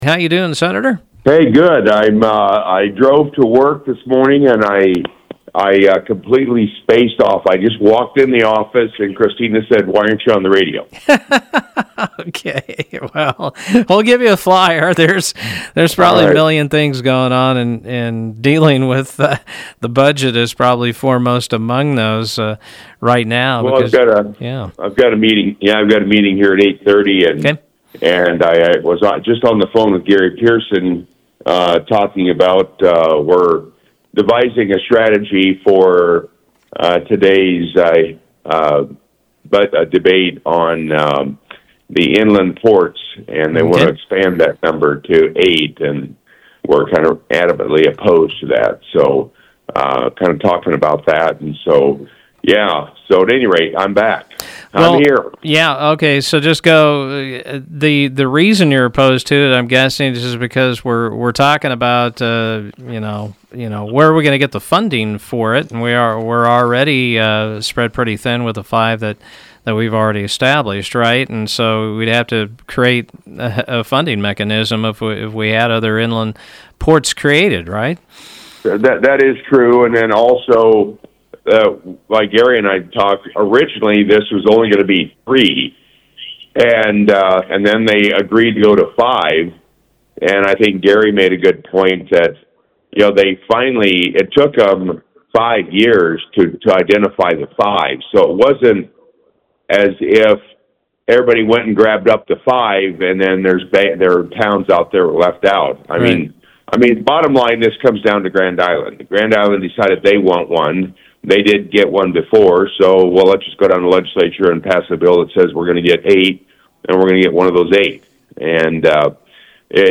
District 42 State Senator Mike Jacobson came on Mugs Monday to talk about how he and his colleagues are working to fix the budget shortfall: